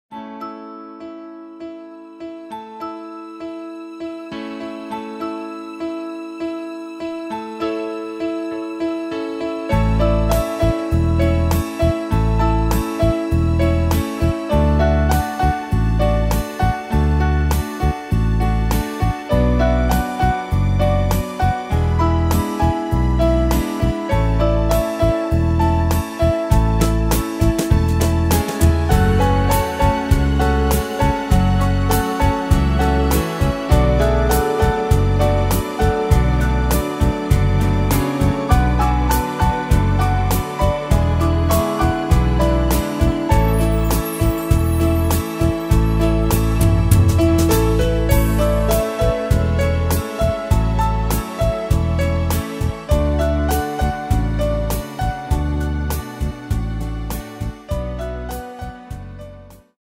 Tempo: 100 / Tonart: A-Dur